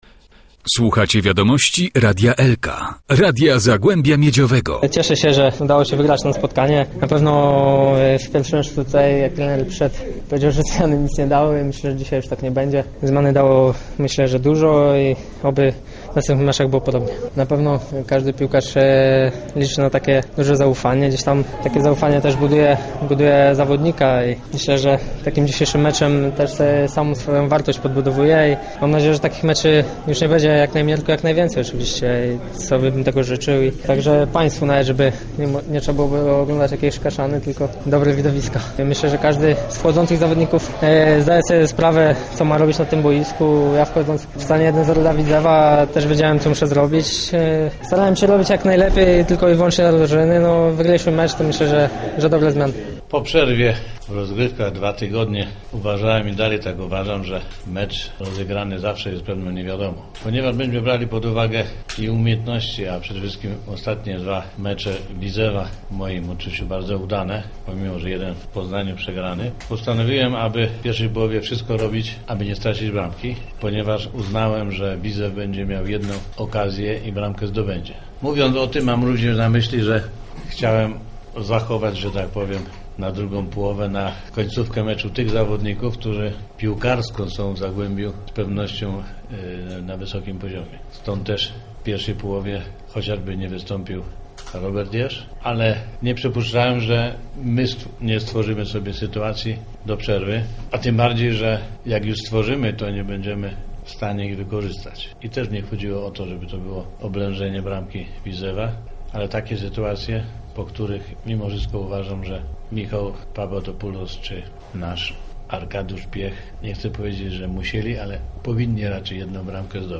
Strzelec dwóch bramek Adrian Błąd i trener Zagłębia, Orest Lenczyk: